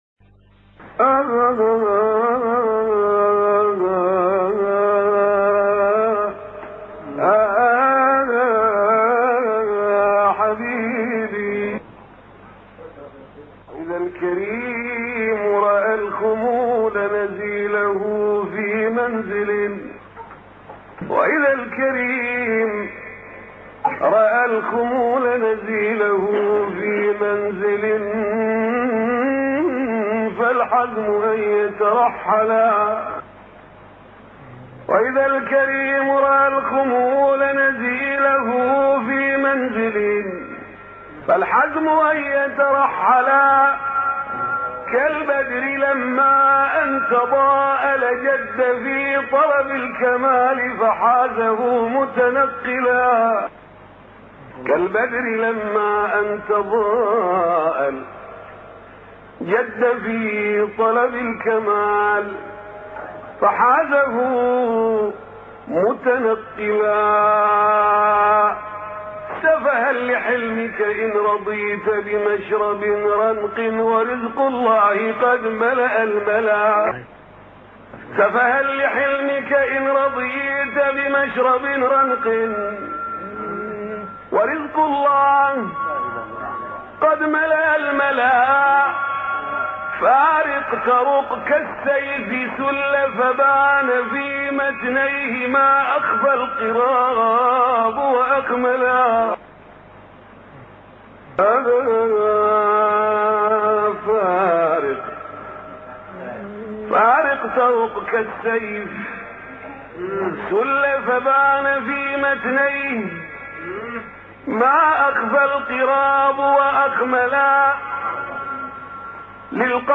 أناشيد